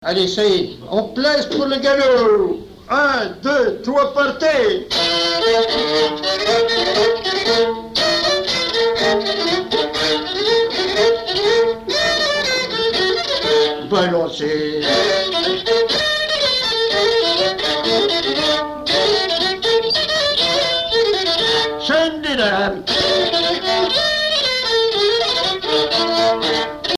danse : quadrille : galop
Pièce musicale inédite